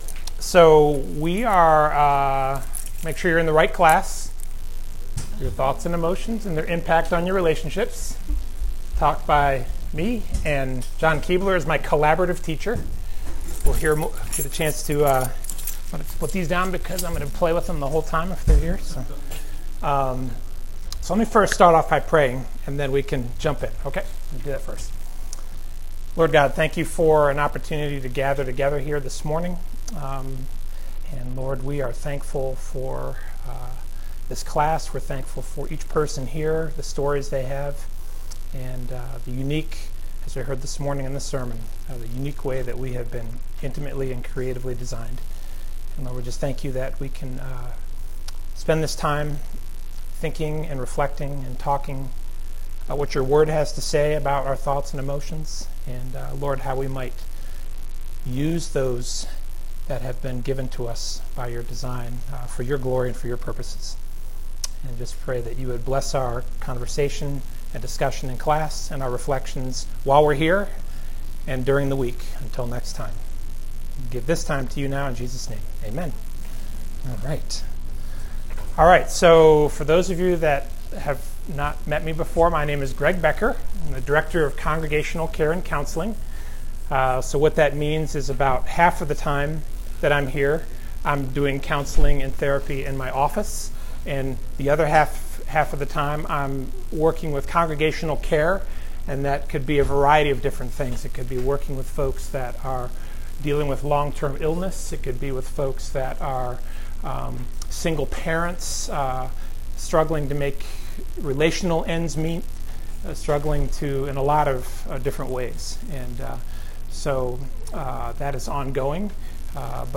Portions of the audio have been edited during times of class interaction due to low sound quality.